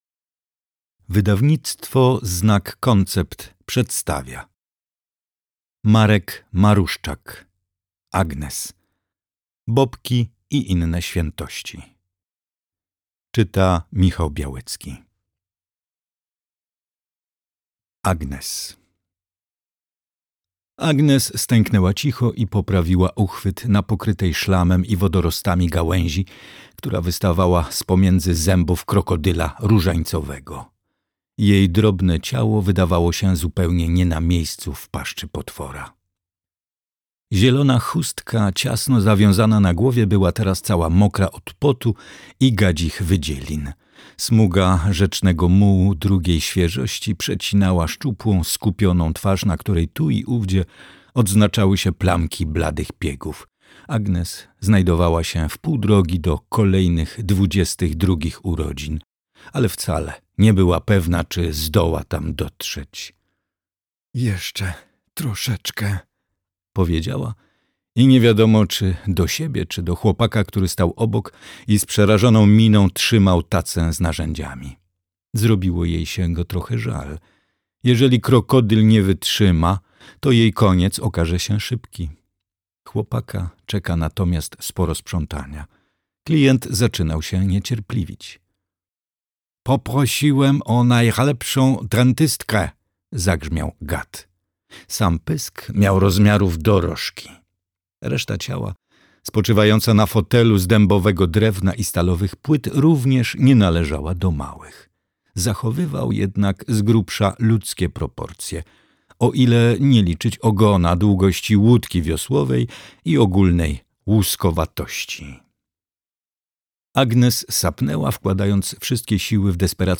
Audiobook + książka Agnes.